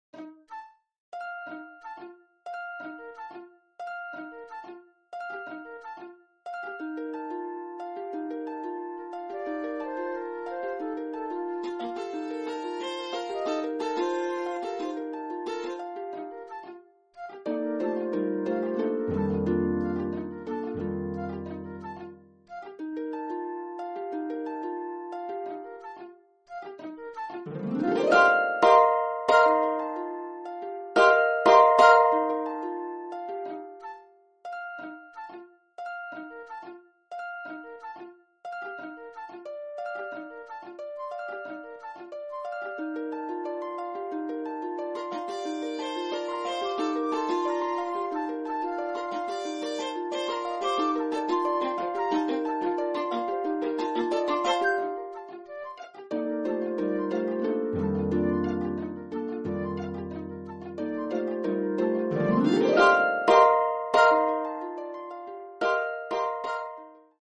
Vivace